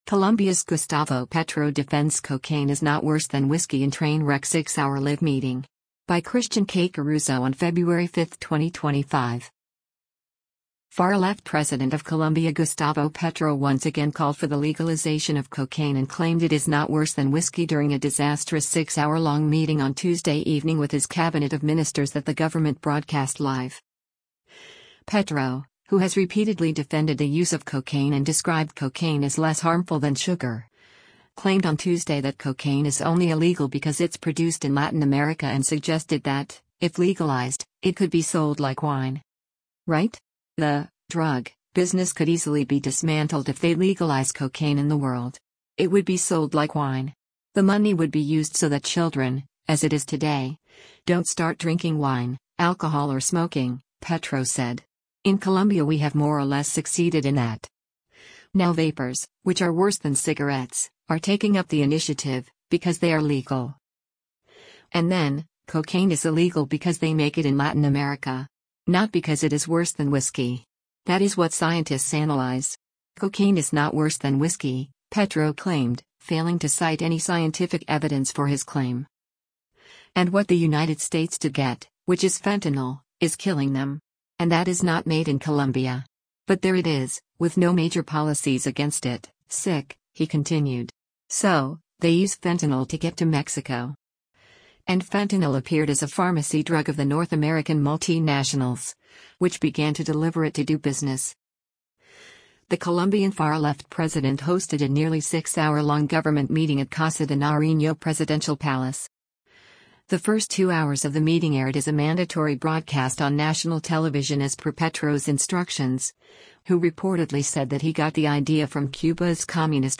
The Colombian far-left president hosted a nearly six-hour-long government meeting at Casa de Nariño presidential palace.
The live government meeting was an unprecedented occurrence in Colombian politics.